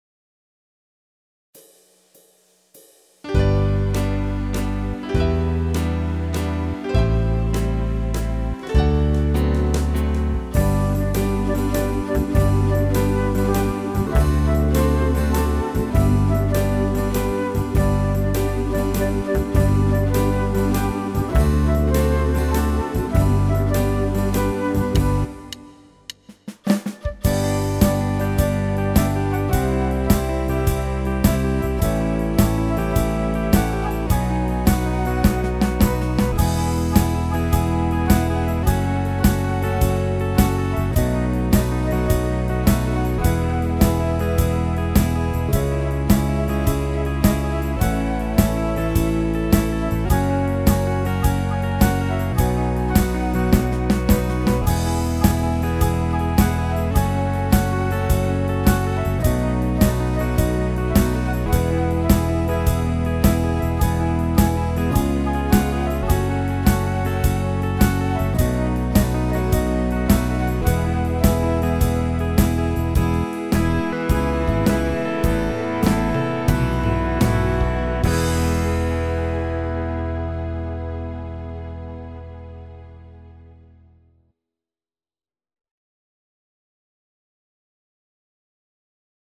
Nasz szkolny hymn mówi o wartościach, jakimi są nauka i współpraca. podkład muzyczny Podczas wykonywania hymnu obowiązuje postawa zasadnicza, zachowanie powagi i spokoju.